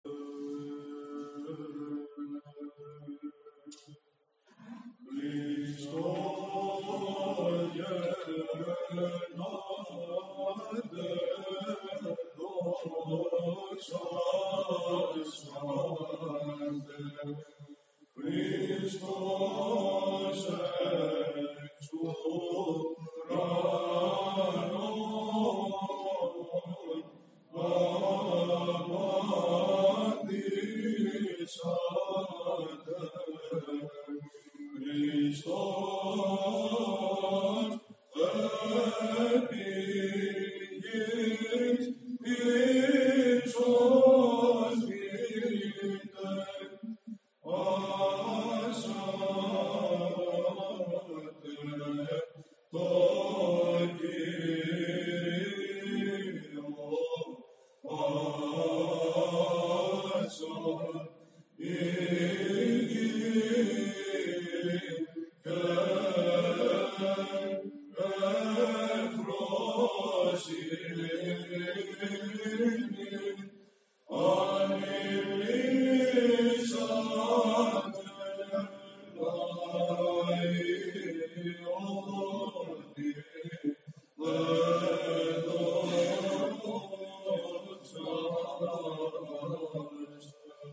Ο εσπερινός ξεκίνησε στις 20:00 με τον ναό να είναι κατάμεστος από πιστούς και το κλίμα θύμιζε αγιορείτικη αγρυπνία.
Ακούστε ένα σύντομο ηχητικό στιγμιότυπο από την Αγρυπνία, την Α’ Ωδή από τις αργές καταβασίες των Χριστουγέννων, ήχος α’, μέλος Πέτρου Πελοποννησίου (+1778):
Χορός ψαλτών στον Άγιο Γεώργιο Καβάλας